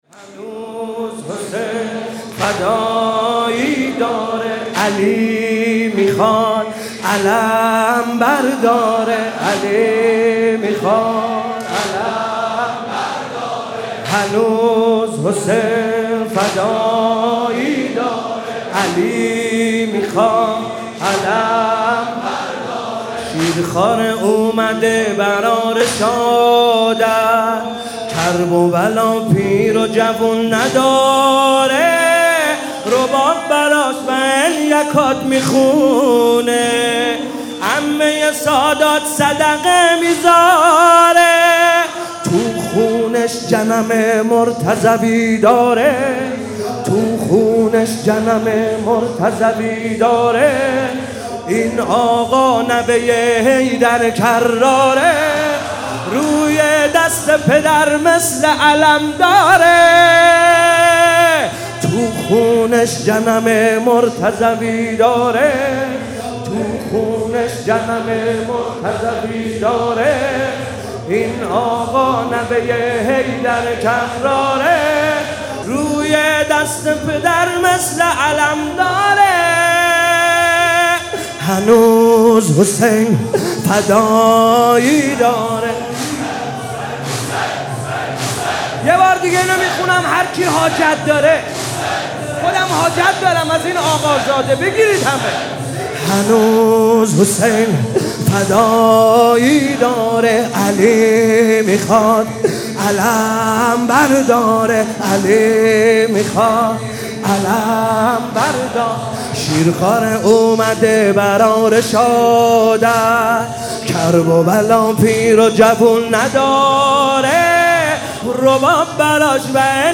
محرم 97 شب هفتم - حسین طاهری - زمینه - هنوز حسین فدایی داره